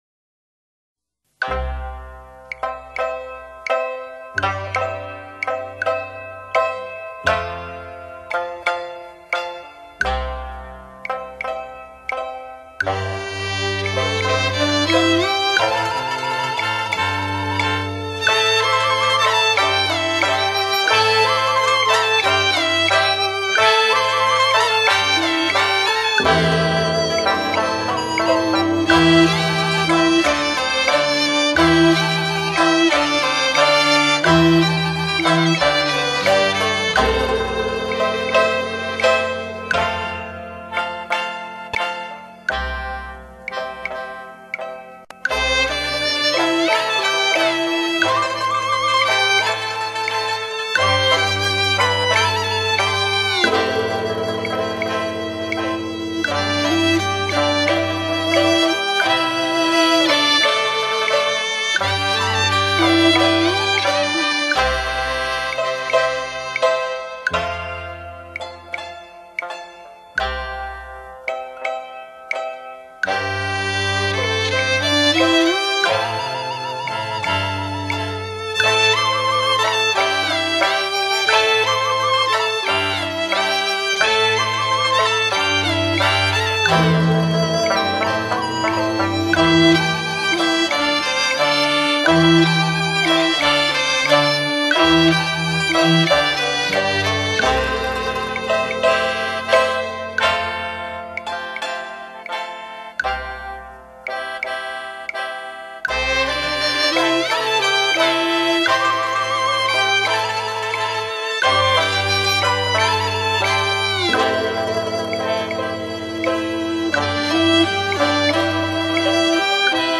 本碟收录了我国优秀的民族乐曲，淡而清雅，可作为肘边首选的时尚休闲音乐！